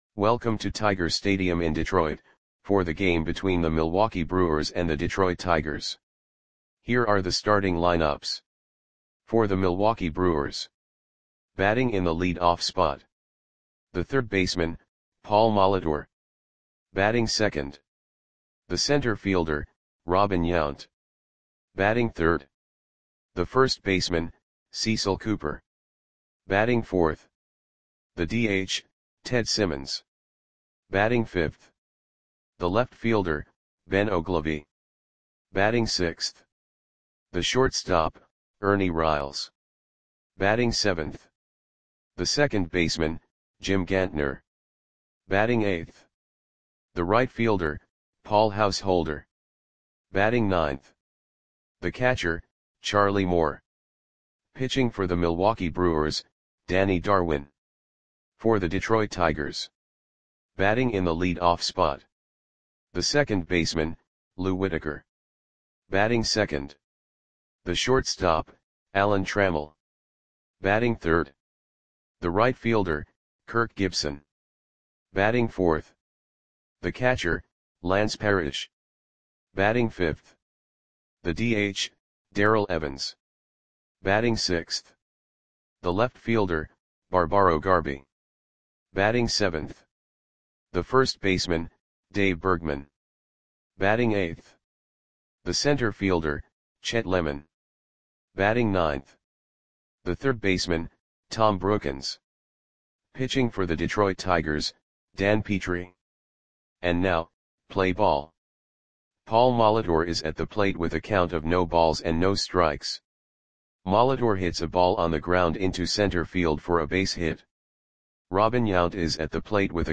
Lineups for the Detroit Tigers versus Milwaukee Brewers baseball game on August 3, 1985 at Tiger Stadium (Detroit, MI).
Click the button below to listen to the audio play-by-play.